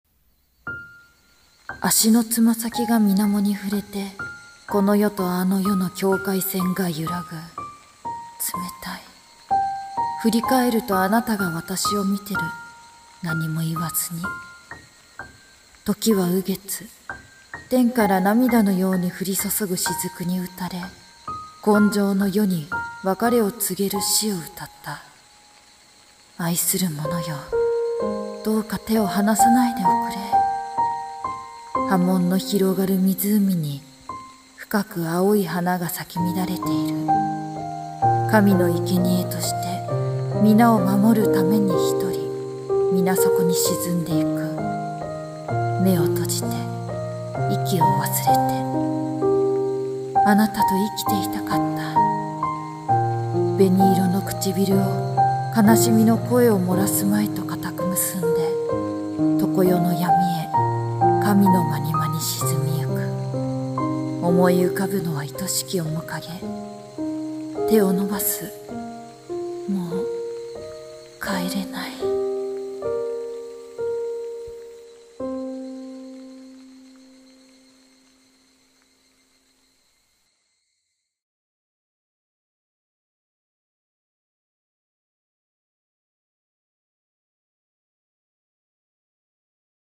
朗読声劇『水葬歌』ジャンル:切ない